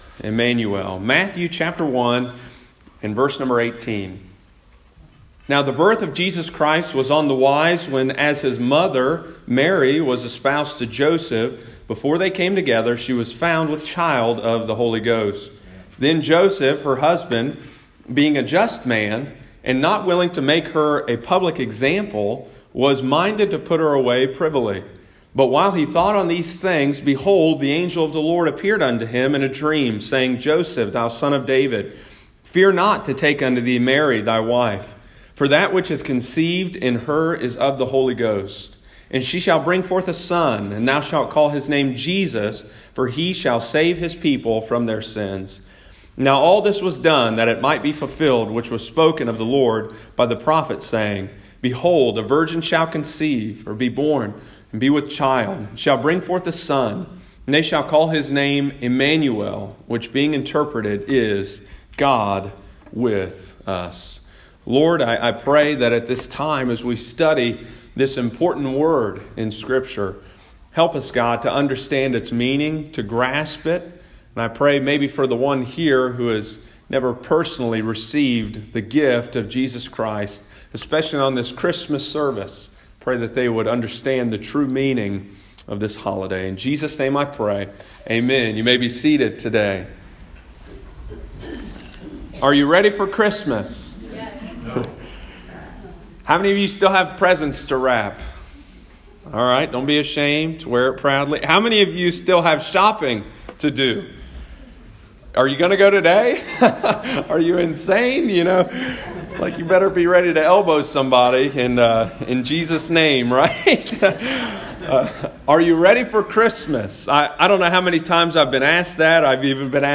preaches during the Christmas service about how Jesus is our Emmanuel.